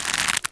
troll_archer_bow_pull.wav